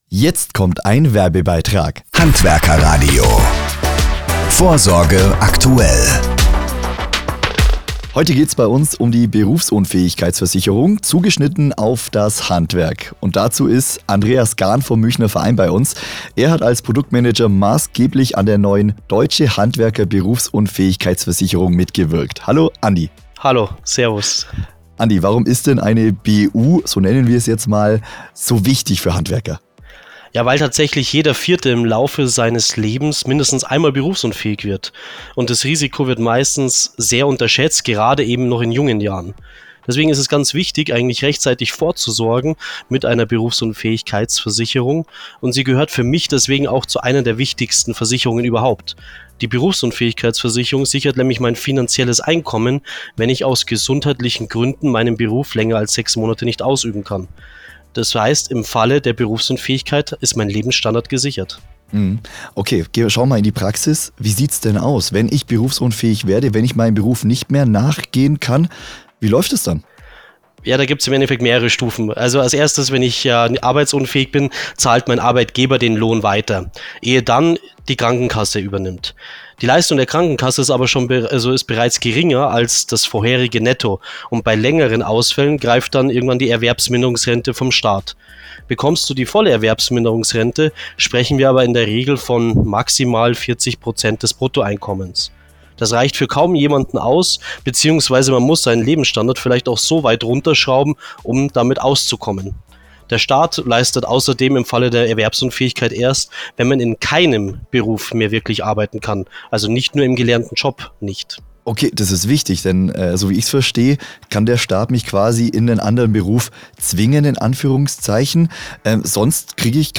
Interview
Praxisthemen aus dem jeweiligen Geschäftsmodell, bezogen auf das Handwerk. Zu Beginn klare Kennzeichnung als Werbebeitrag.
Muenchener-Verein_Interview_Beispiel.mp3